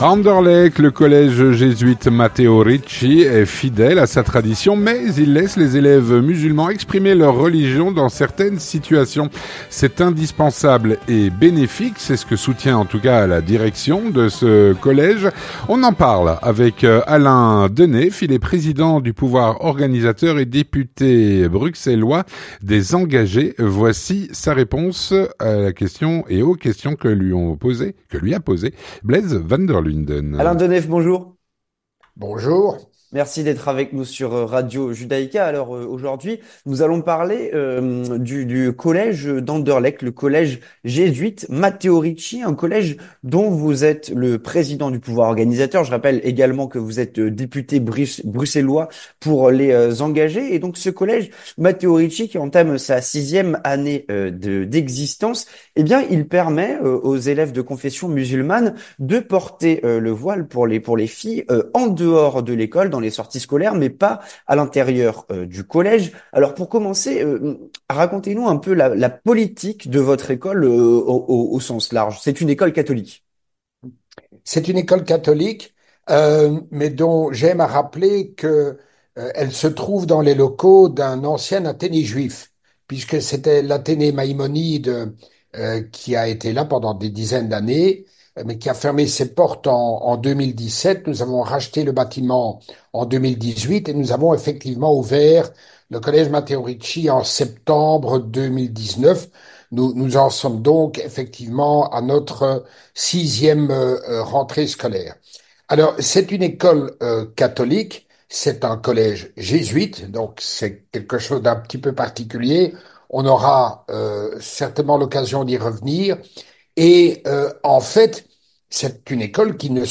On en parle avec Alain Deneef, président du pouvoir organisateur et député bruxellois des Engagés.